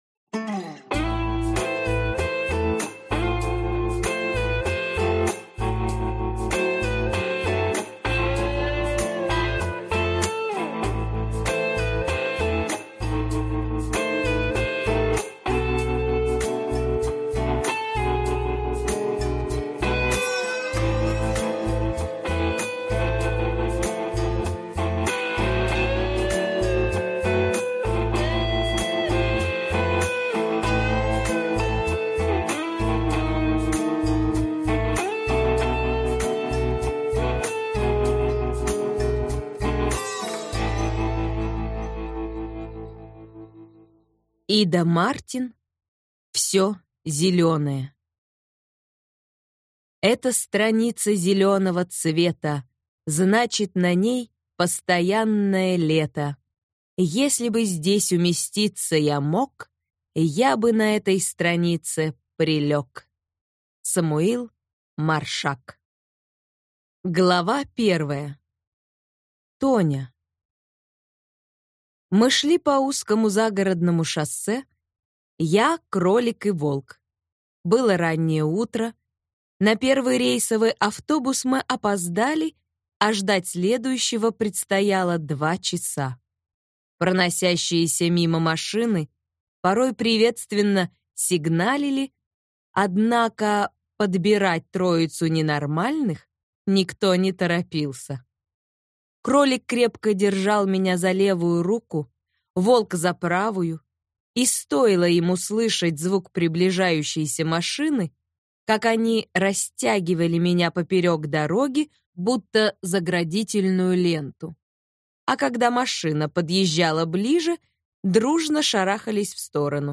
Аудиокнига Всё зелёное | Библиотека аудиокниг
Прослушать и бесплатно скачать фрагмент аудиокниги